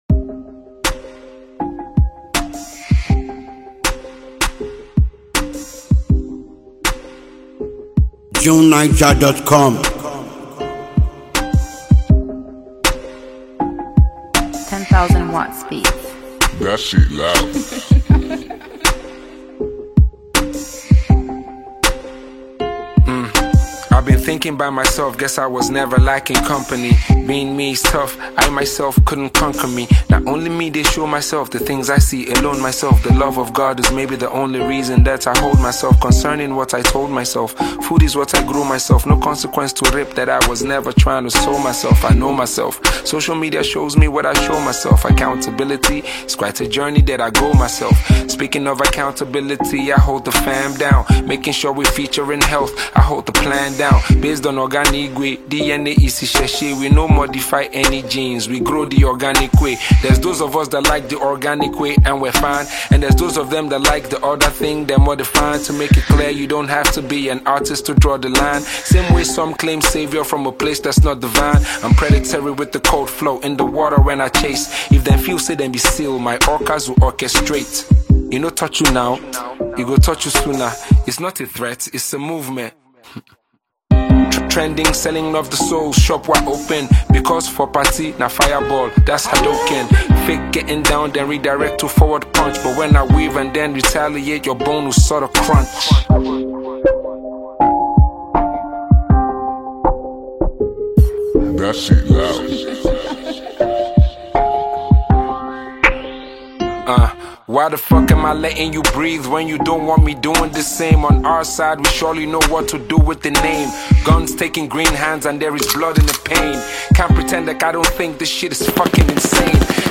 a legendary Nigerian poet and rap artist